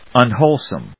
un・whole・some /`ʌnhóʊlsəm‐hˈəʊl‐/
• / `ʌnhóʊlsəm(米国英語)